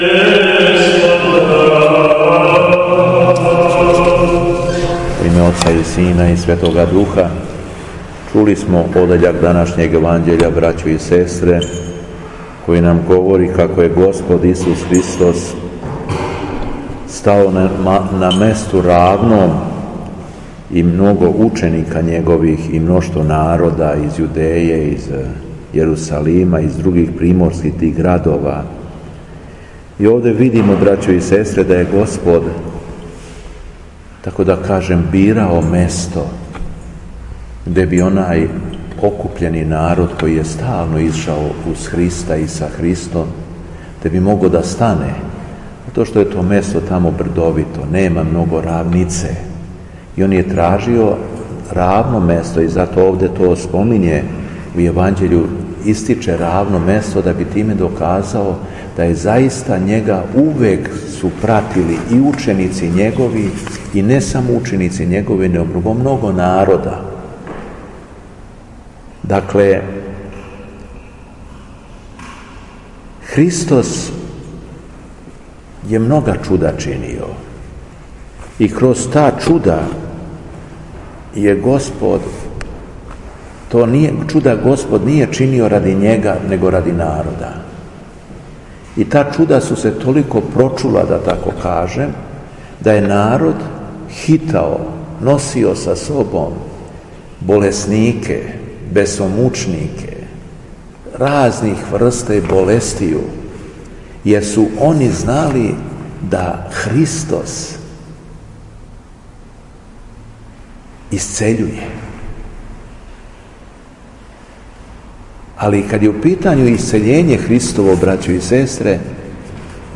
У петак, 11. октобра 2019. године, Његово Преосвештенство Епископ шумадијски Господин Јован служио је свету архијерејску Литургију у храму Свете Петке...
Беседа Епископа шумадијског Г. Јована